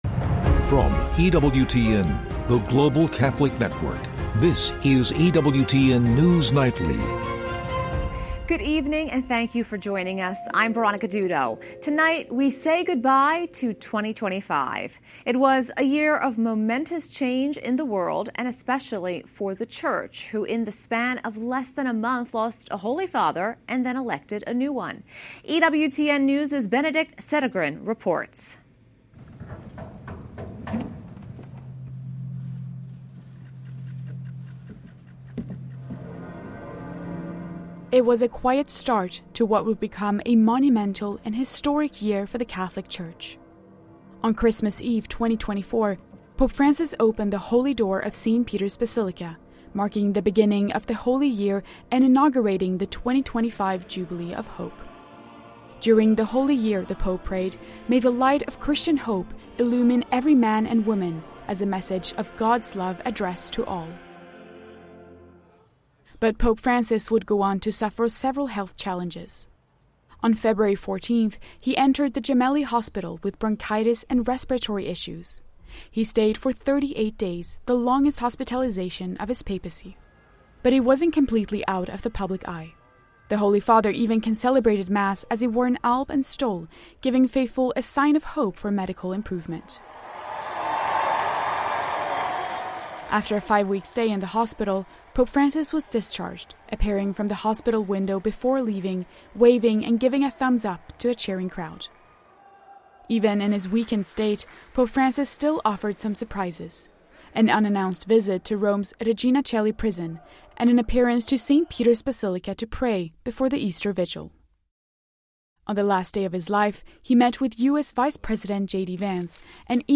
1 Karyn_White_Interview 28:45